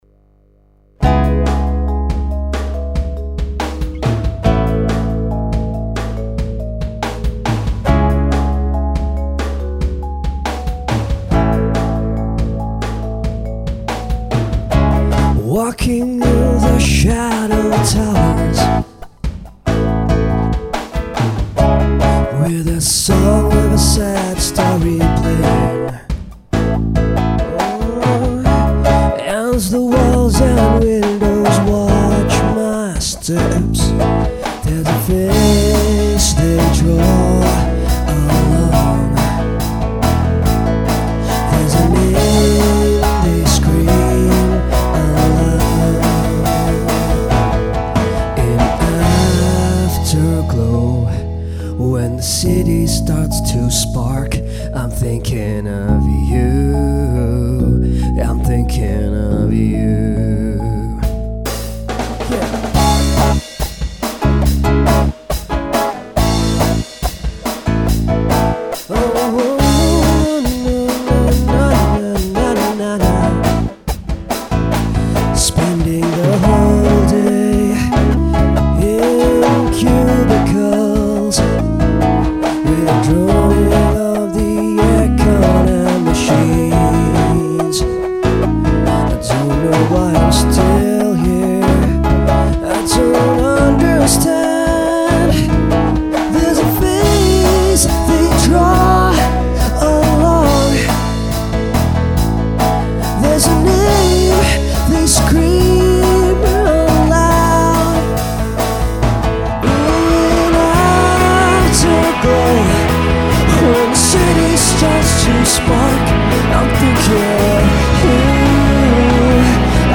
recorded at Dubai Marina